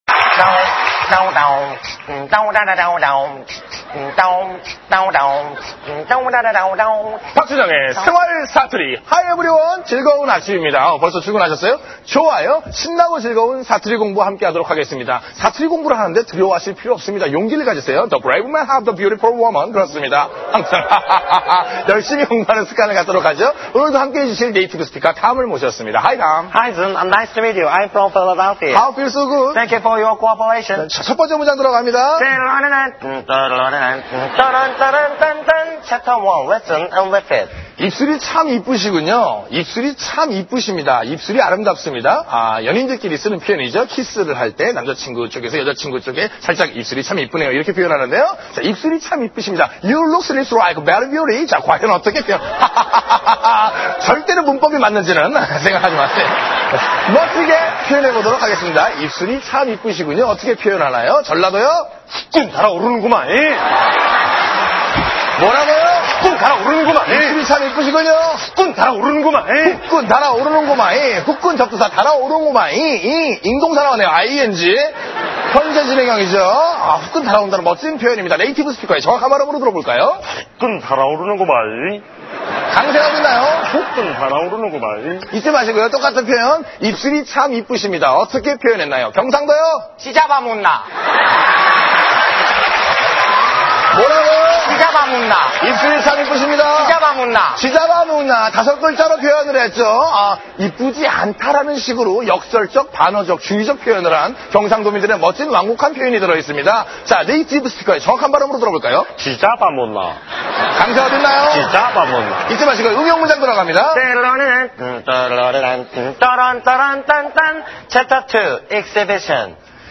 [318] 생활 사투리 2, 3, 4편들... ^^
생활사투리4.mp3